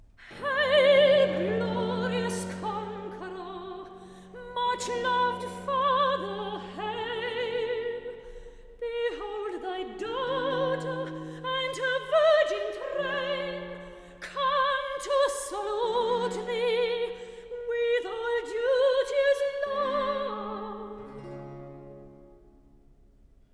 Recitative